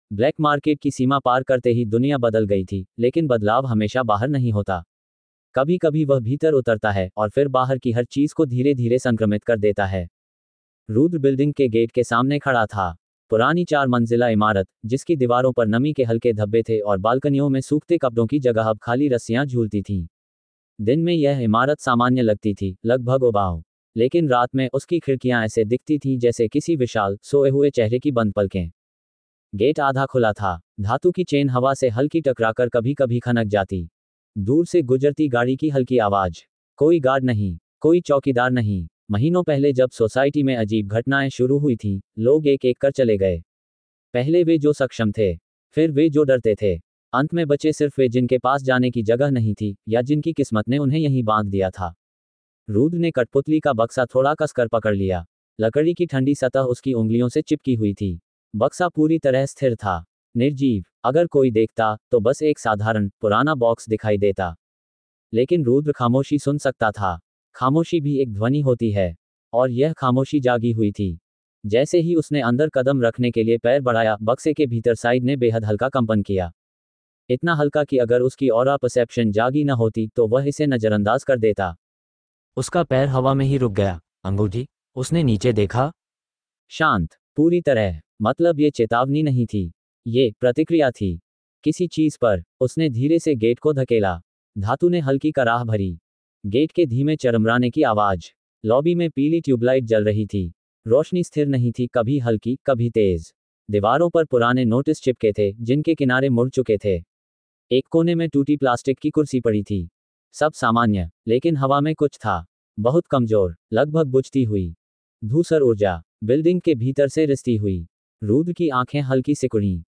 AudioTaleFM – Premium Sci-Fi, Fantasy & Fairy Tale Audio Stories